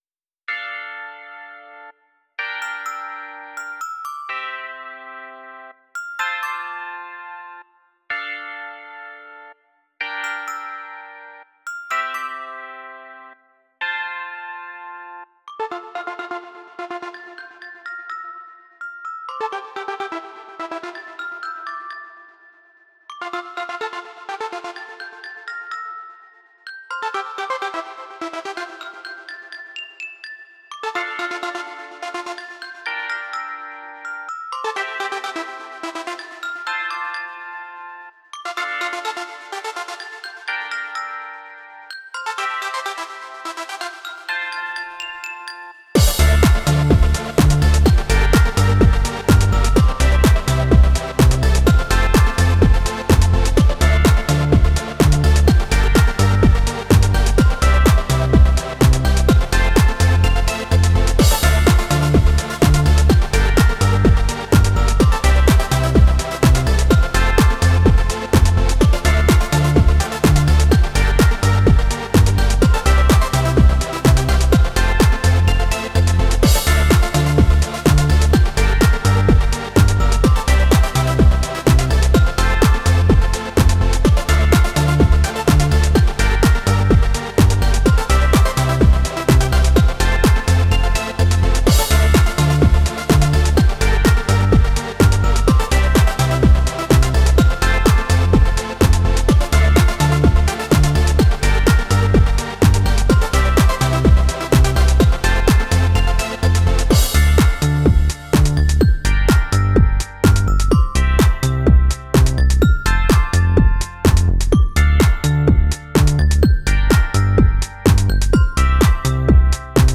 BEST ELECTRO G-Q (39)